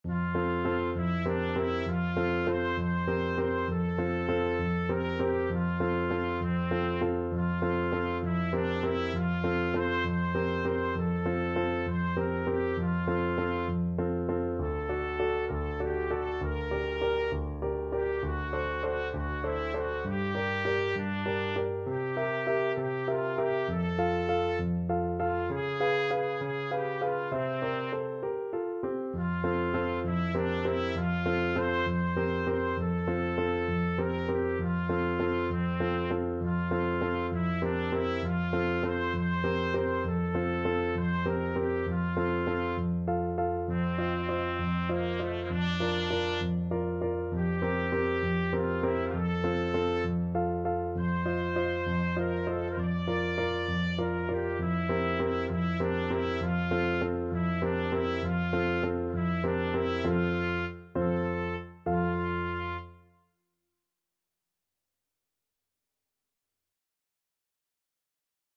Trumpet
F major (Sounding Pitch) G major (Trumpet in Bb) (View more F major Music for Trumpet )
. = 66 No. 3 Grazioso
6/8 (View more 6/8 Music)
Classical (View more Classical Trumpet Music)